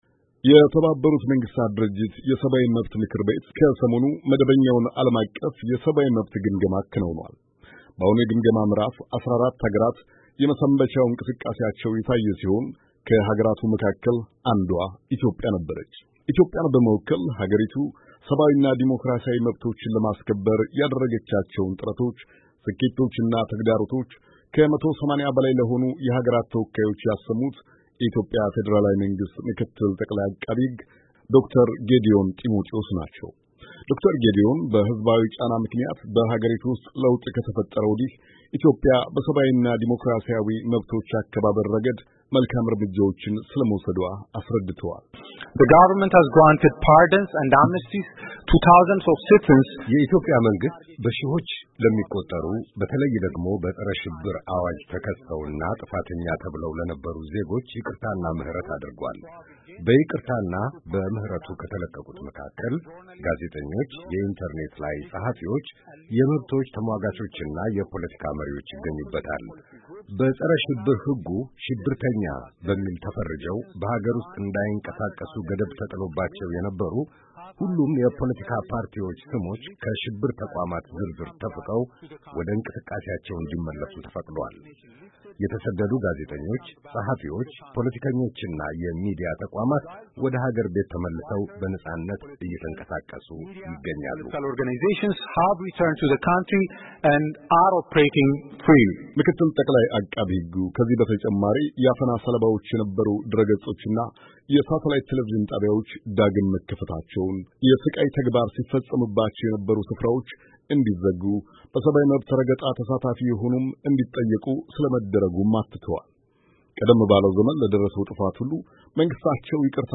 ዶ/ር ጌዲዮዎን ጤሞጢዎስ የኢትዮጵያን ሪፖርት ለተመድ የሰብአዊ መብት ም/ቤት አሰምተዋል፡፡